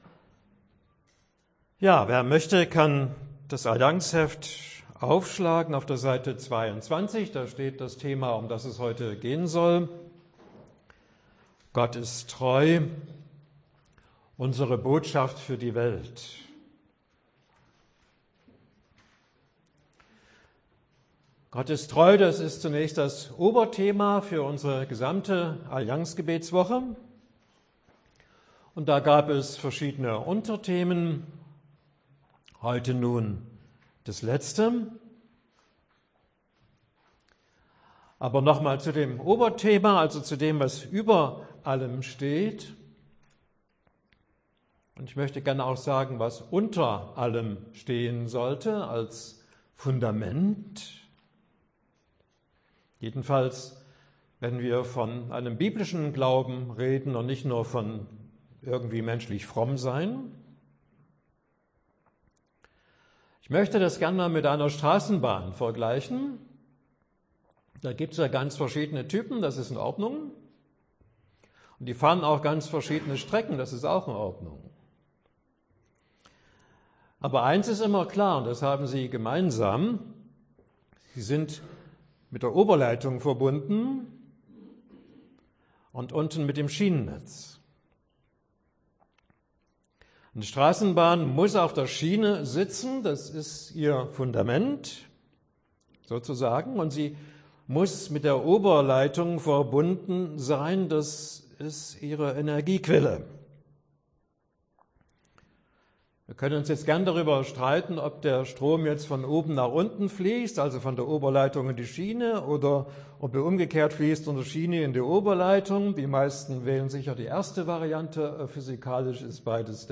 Allianzgebetswoche 2026 – Abschlussgottesdienst
Predigten